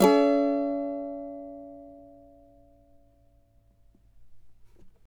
CAVA D#MN  U.wav